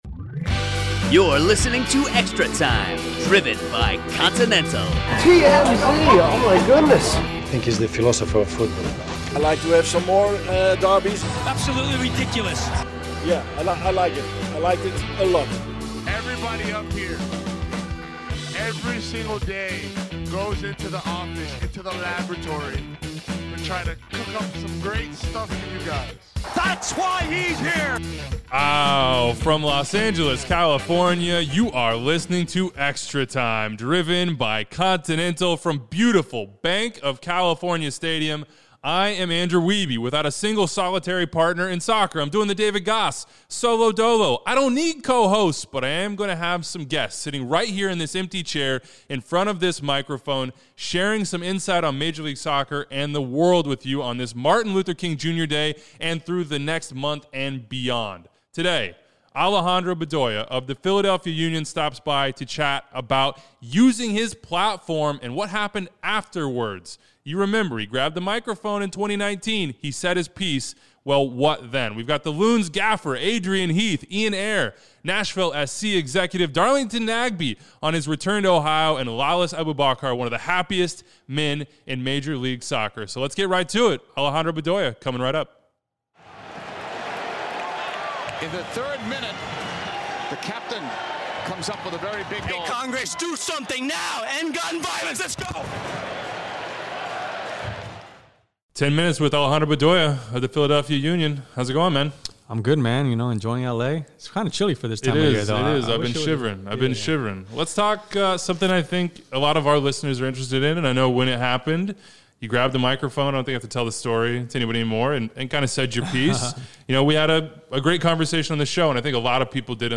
Interview special! 10 minutes w